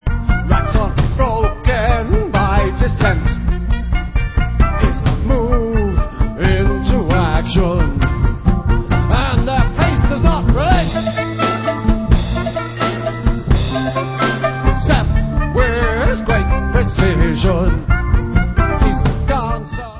sledovat novinky v oddělení Rock/Progressive